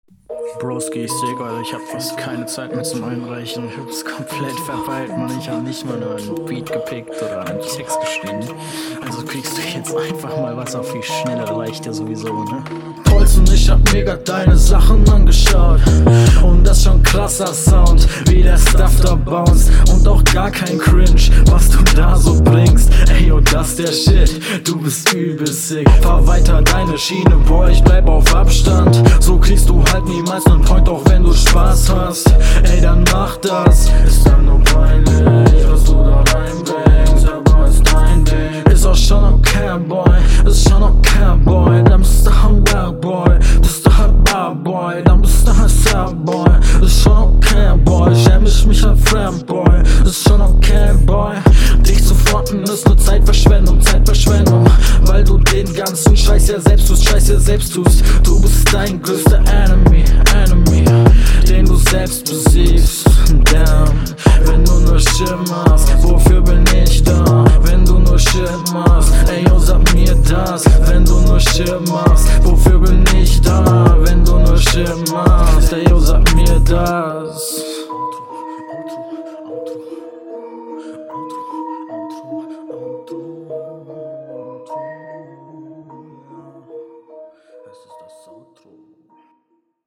Sound wieder gut.